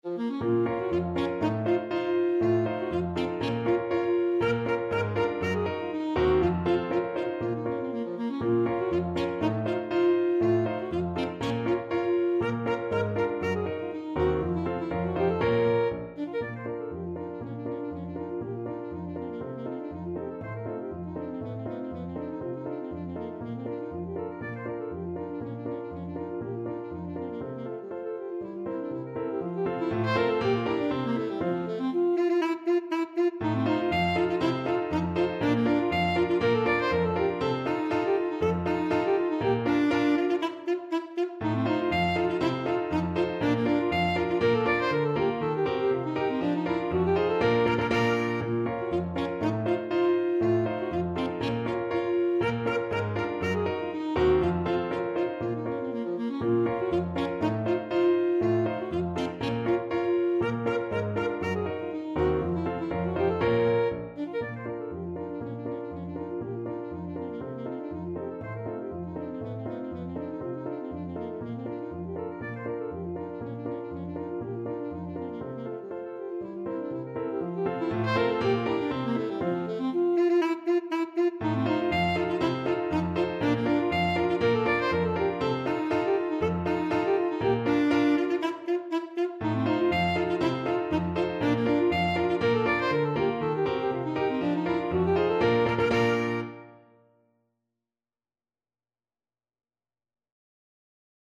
Classical Rachmaninoff, Sergei Polka Italienne Alto Saxophone version
Alto Saxophone
Bb minor (Sounding Pitch) G minor (Alto Saxophone in Eb) (View more Bb minor Music for Saxophone )
2/4 (View more 2/4 Music)
F4-F6
Classical (View more Classical Saxophone Music)